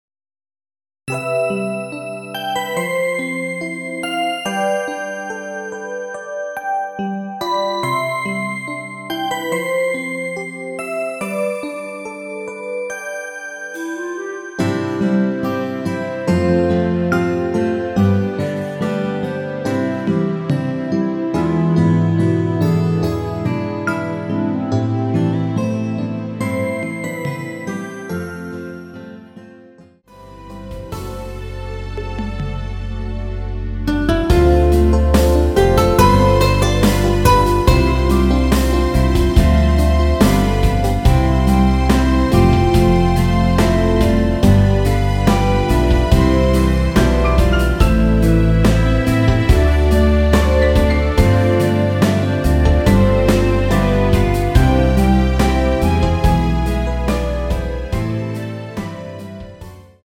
노래방에서 노래를 부르실때 노래 부분에 가이드 멜로디가 따라 나와서
멜로디 MR이라고 합니다.
앞부분30초, 뒷부분30초씩 편집해서 올려 드리고 있습니다.
중간에 음이 끈어지고 다시 나오는 이유는